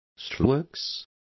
Complete with pronunciation of the translation of steelworks.